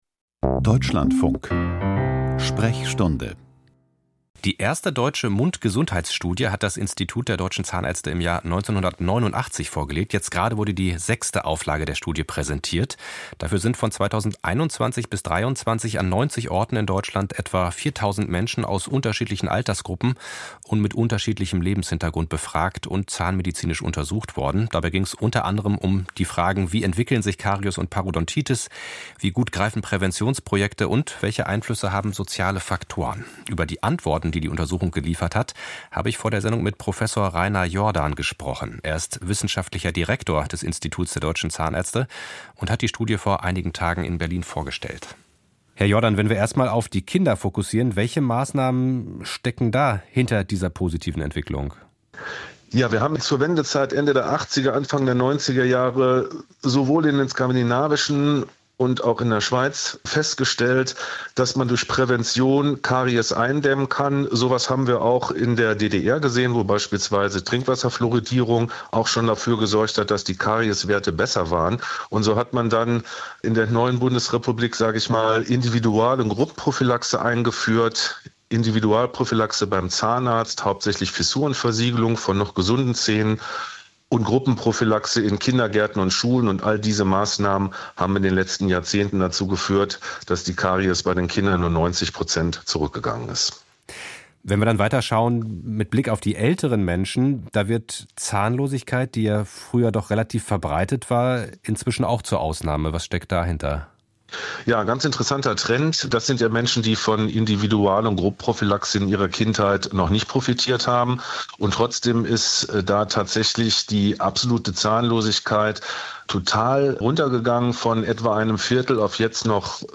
Ein Experte gibt Auskunft über den Erkenntnisstand seines medizinischen Fachgebietes und beantwortet anschließend Hörerfragen; die Sendung wird ergänzt durch einen aktuellen Info-Block.